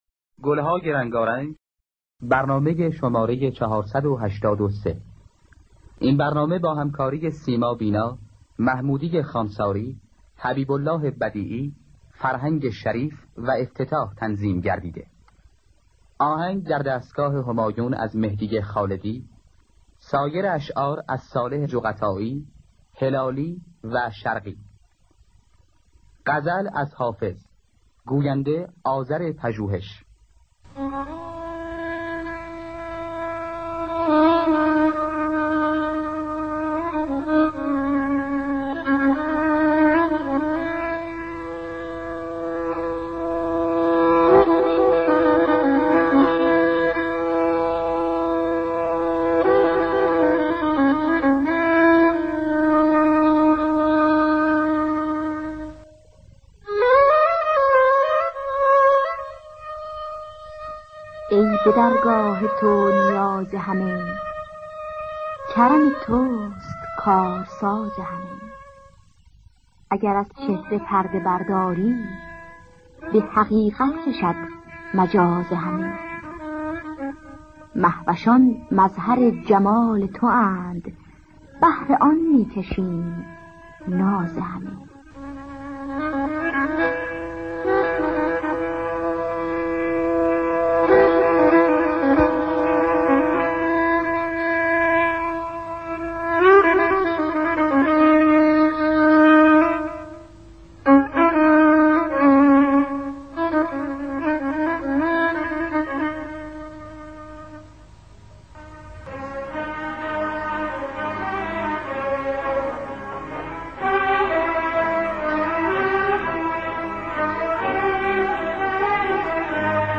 در دستگاه همایون